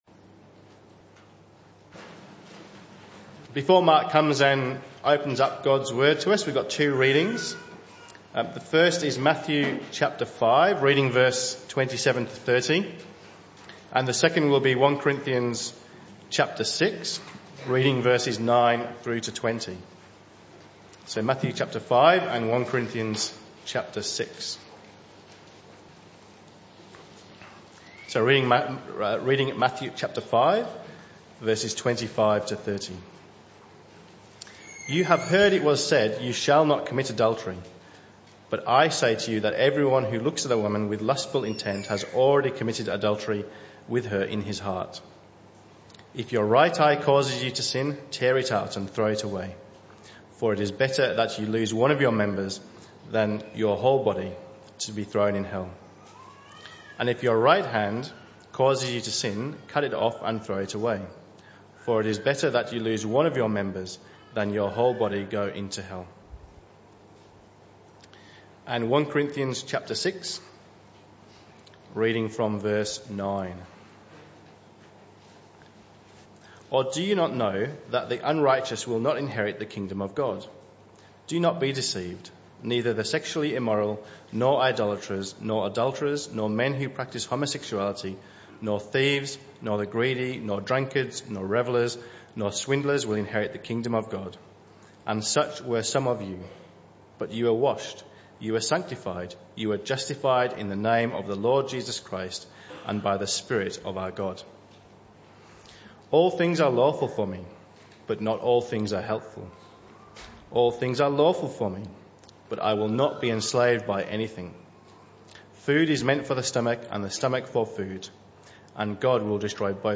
This talk was part of the AM & PM Service series entitled 7 Deadly Sins (Talk 5 of 8).